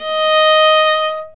Index of /90_sSampleCDs/Roland - String Master Series/STR_Viola Solo/STR_Vla3 Arco nv
STR VIOLA 0H.wav